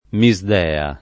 [ mi @ d $ a ]